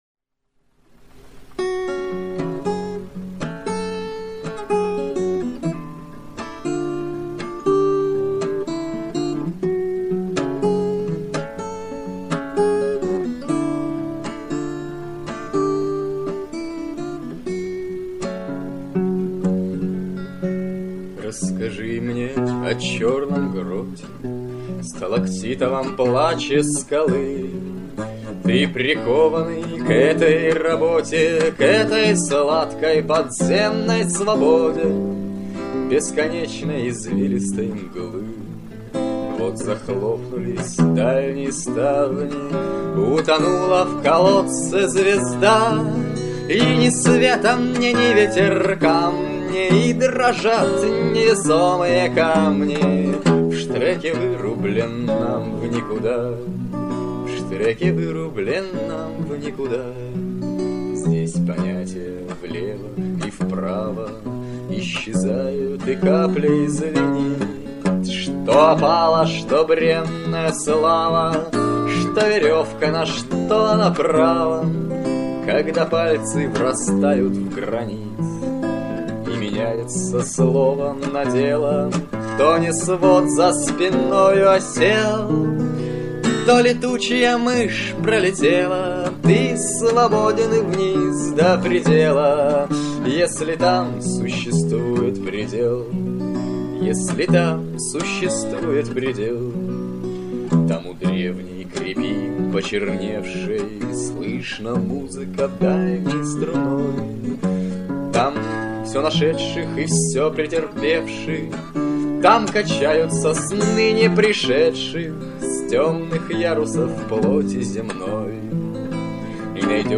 На одной старой кассете в мамином архиве нашёлся целый комплект всякого добра из 1995 года.